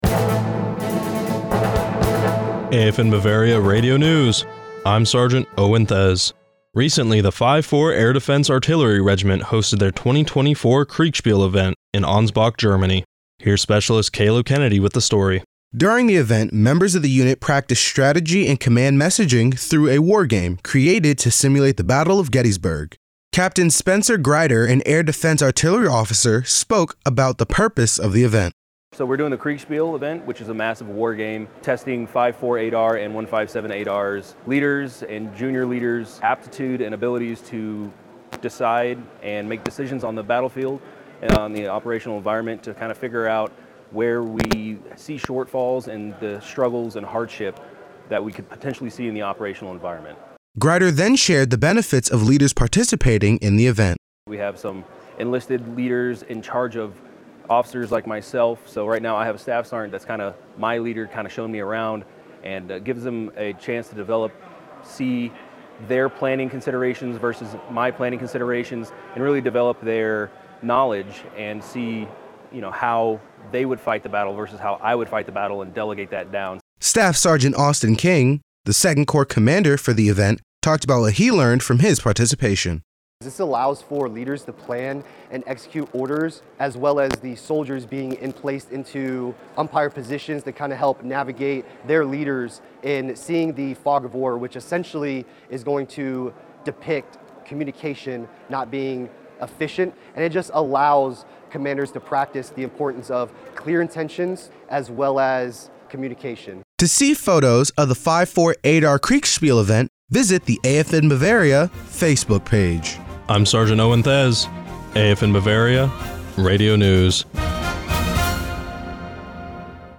AFN Bavaria Radio News June 7, 2024